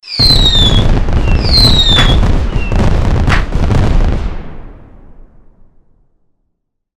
Fireworks2.wav